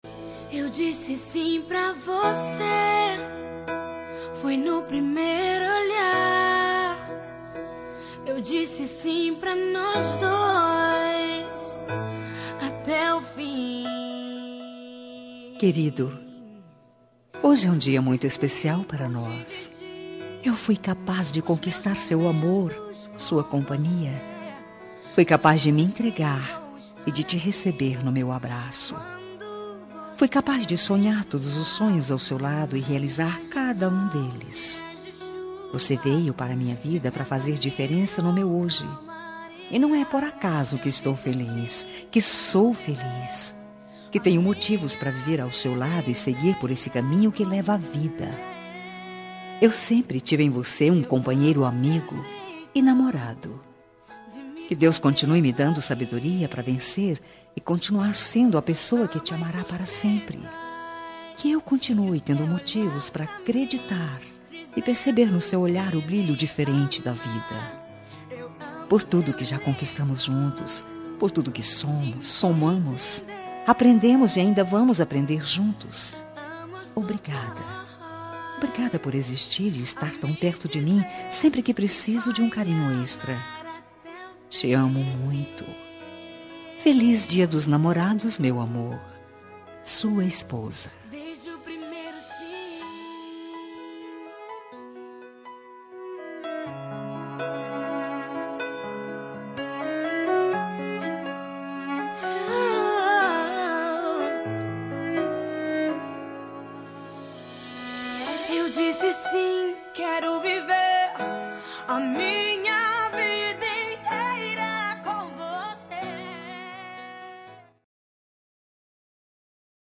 Dia dos Namorados – Para Marido – Voz Feminina – Cód: 6895